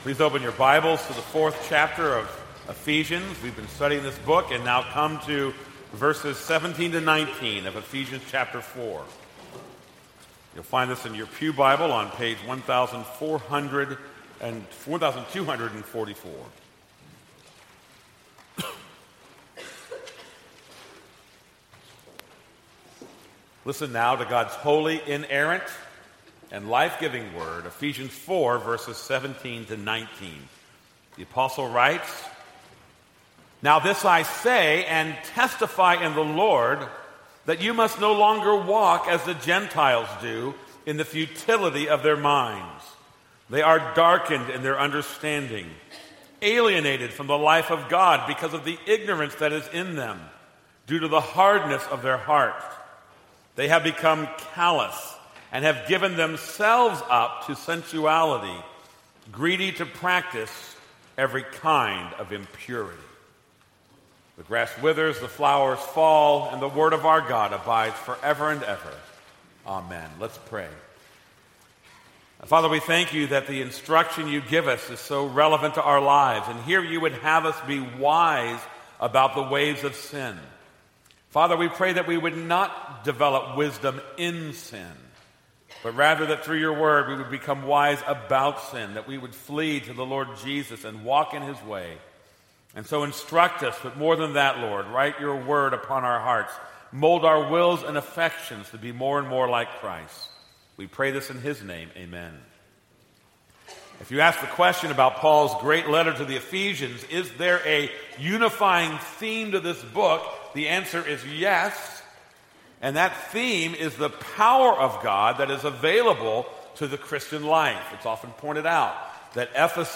This is a sermon on Ephesians 4:17-19.